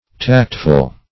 Tactful \Tact"ful\, a.